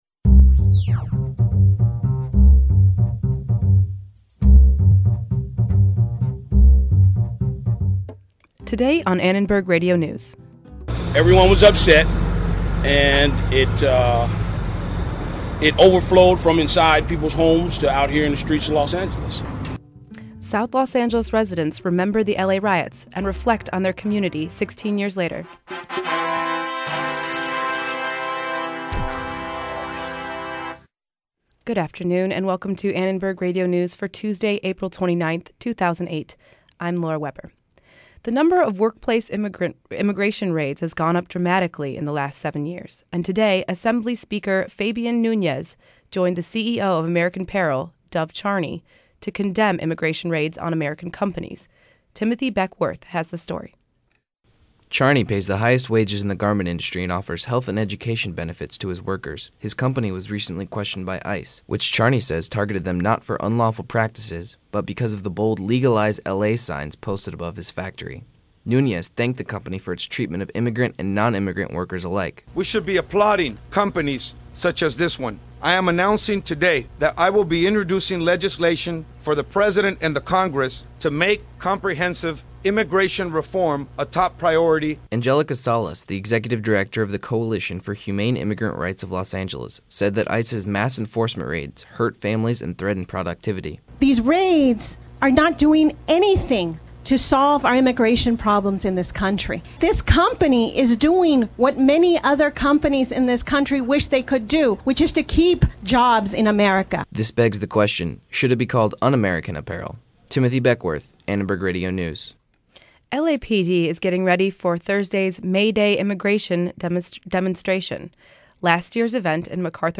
On the 16th anniversary of the L.A. Riots, hear from community members about what's changed, and what hasn't. And the race for an open seat on the L.A. county Board of Supervisors heats up with a debate. We have a recap, plus analysis on what it means for both candidates.